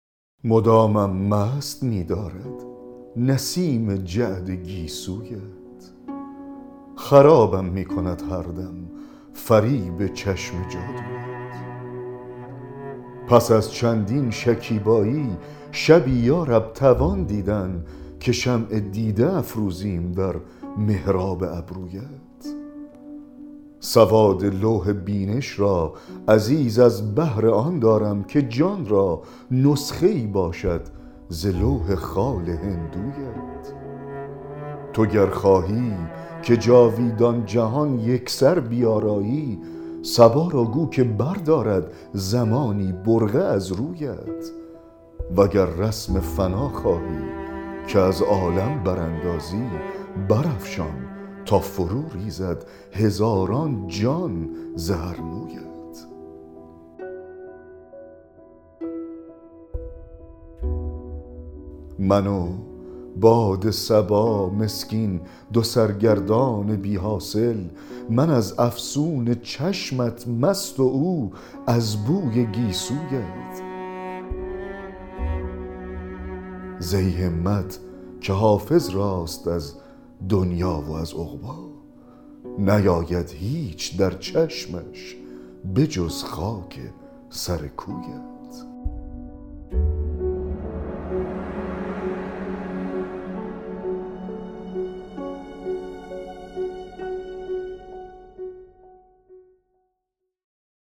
دکلمه غزل 95 حافظ
دکلمه-غزل-95-حافظ-مدامم-مست-می-دارد-نسیم-جعد-گیسویت.mp3